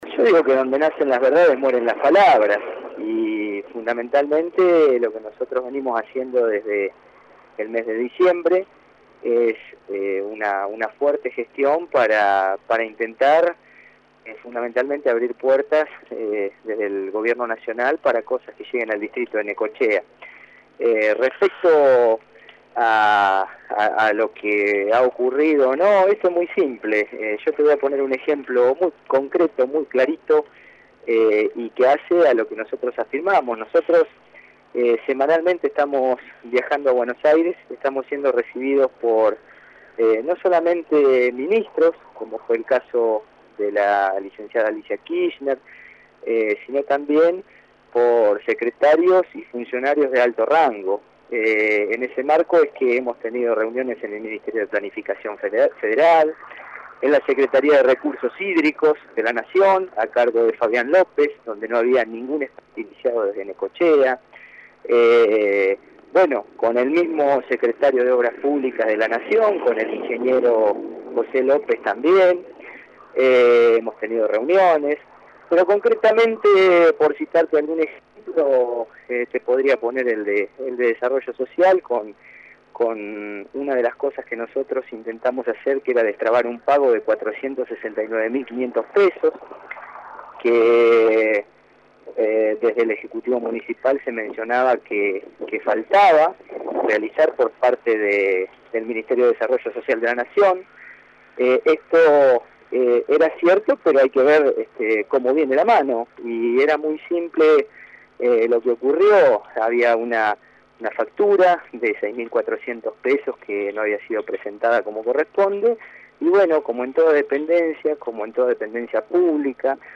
El Concejal Horacio Tellechea habló en exclusiva con JNFNet sobre temas de actualidad.